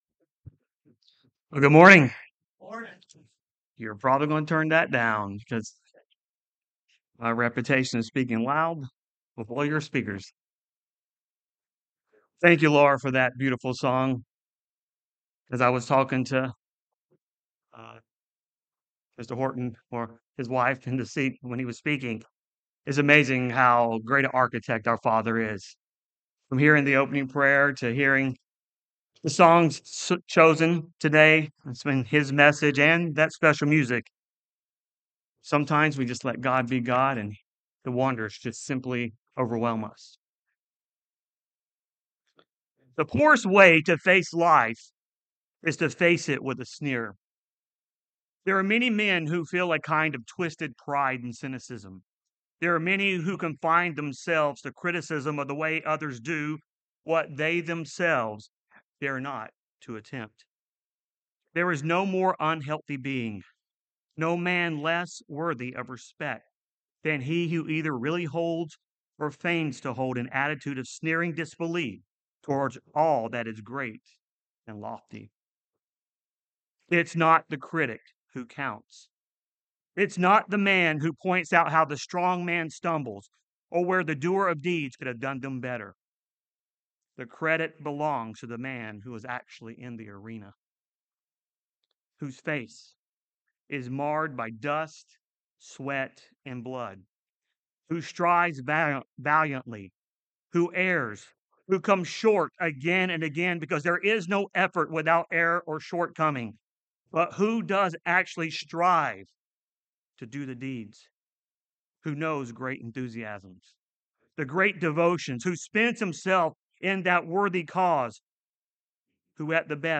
This sermon was given at the Cincinnati, Ohio 2024 Feast site.